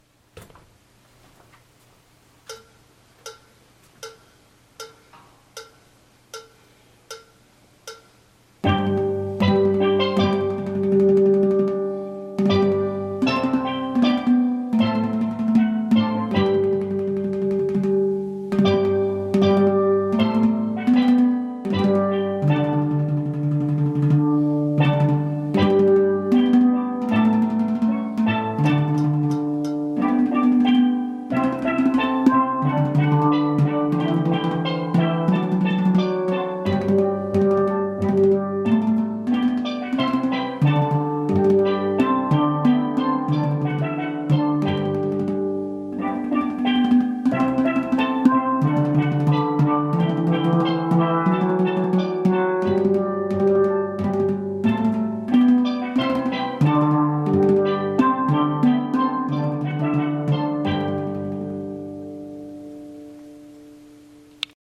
Guitar Come Again Vid 78 90 .mp3